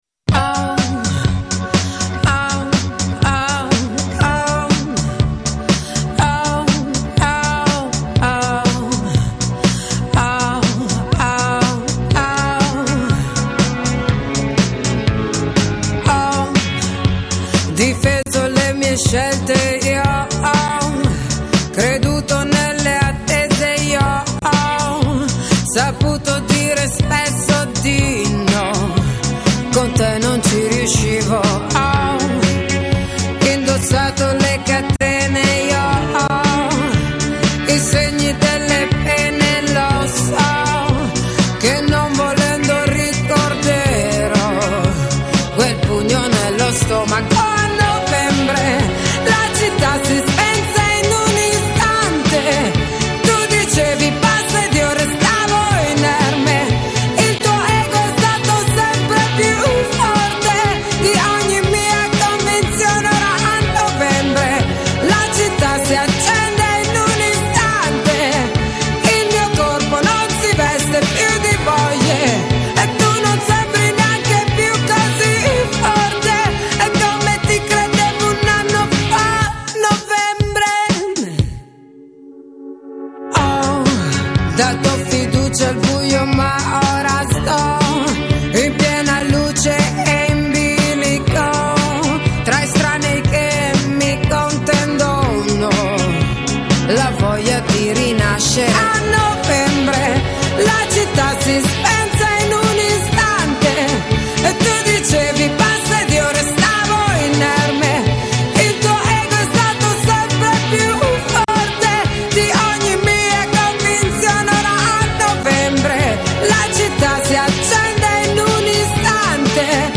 唱出来的风格也非常特别,
沙哑中有点像六十七年代的非州祖母歌手，
沙哑的声音，乍一听，感觉不怎么好，
以流行音乐、蓝调、摇滚，还参杂点迷幻。